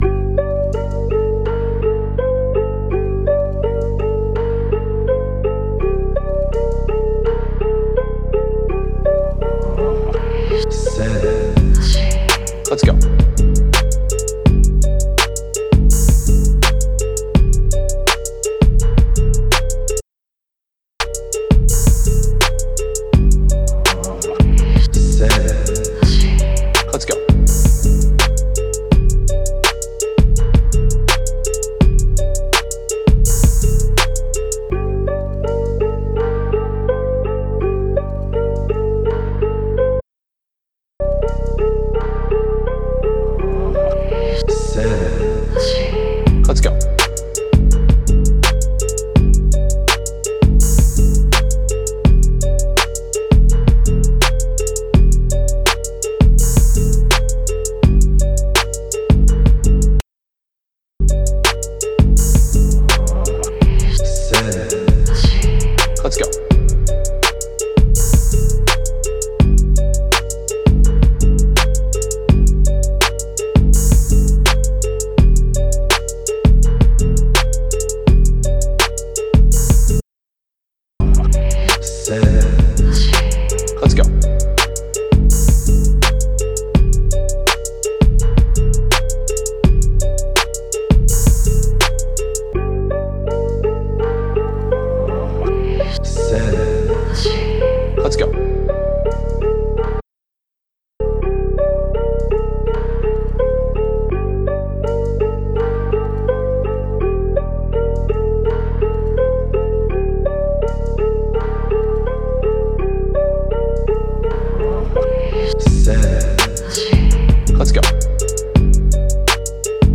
Bouncy – Dark – Type Beat
Key: G Minor
160 BPM